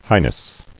[high·ness]